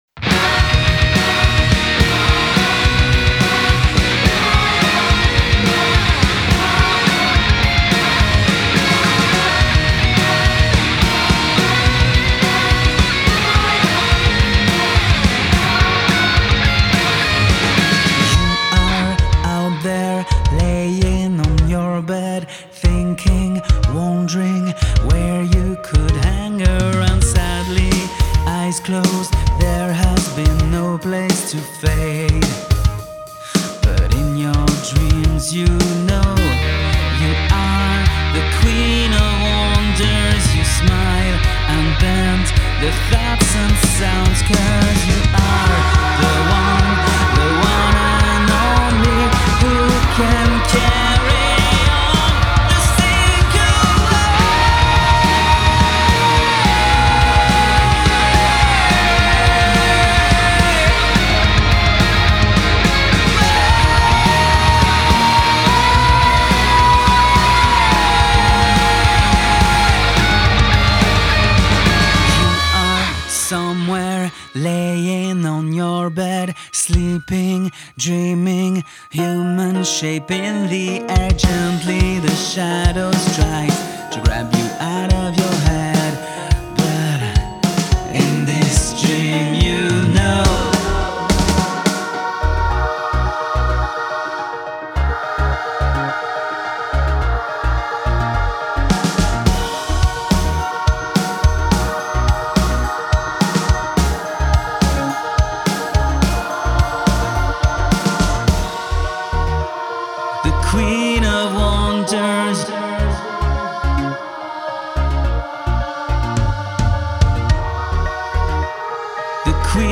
Genre : Rock.